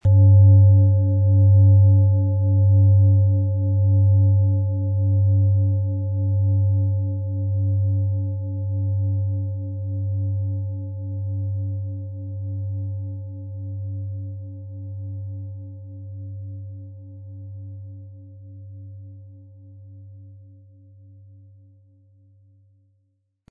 Planetenton 1
Spielen Sie die Pluto mit dem beigelegten Klöppel sanft an, sie wird es Ihnen mit wohltuenden Klängen danken.
MaterialBronze